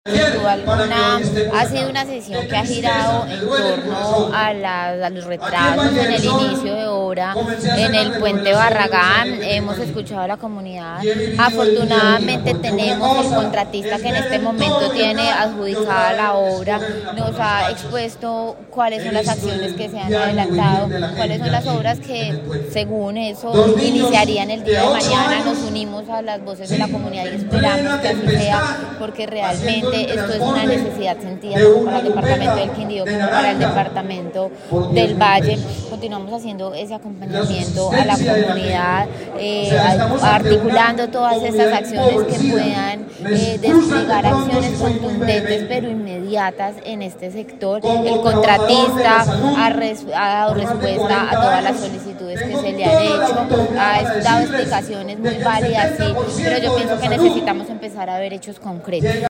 En sesión descentralizada de la honorable Asamblea Departamental, ampliada con líderes comunales y la veeduría cordillerana, se anunció que este viernes 14 de abril se iniciarán las labores de intervención en el puente de Barragán, con la expectativa de que en aproximadamente dos meses estén terminadas.
Audio de Juana Camila Gómez Zamorano, secretaria del Interior: